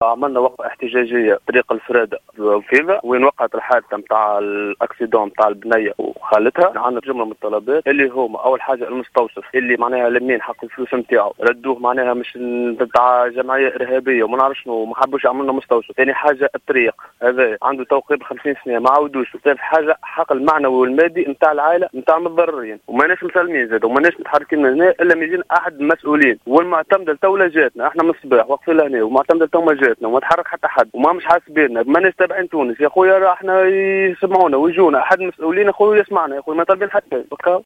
ويطالب المحتجون بمستوصف وتهيئة طريق الفرادة، إلى جانب تمكين عائلة المتضررتين بالحق المعنوي والمادي، وفق تصريح أحد المحتجين للجوهرة اف ام.